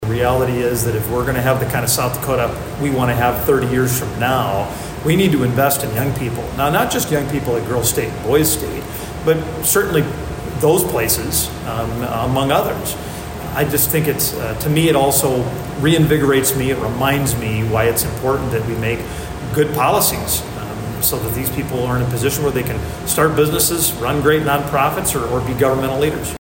VERMILLION, S.D.(KYNT)- South Dakota Governor Larry Rhoden and Representative Dusty Johnson spoke at South Dakota Girls State on the University of South Dakota campus in Vermillion on Wednesday.